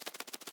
latest / assets / minecraft / sounds / mob / parrot / fly3.ogg
fly3.ogg